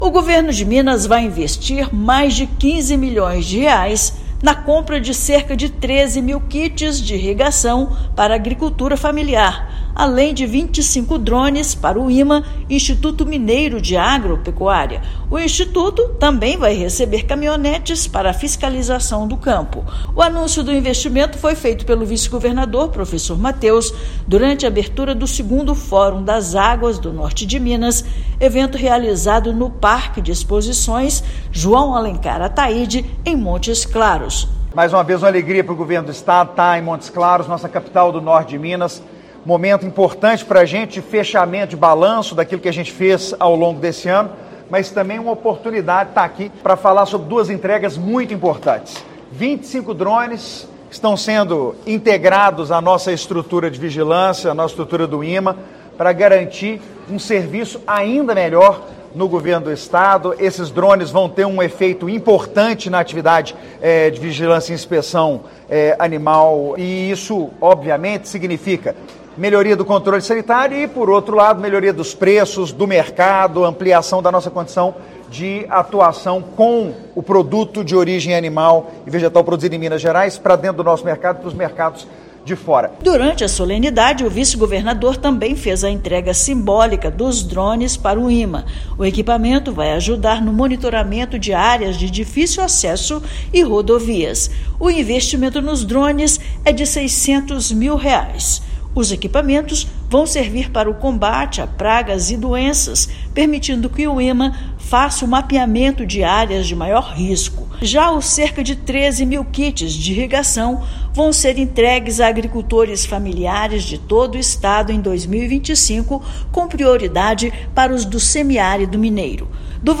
Até 2026, serão entregues 20 mil kits, totalizando investimentos de mais de R$ 25,9 milhões. O Instituto Mineiro de Agropecuária (IMA) receberá drones e veículos. Ouça matéria de rádio.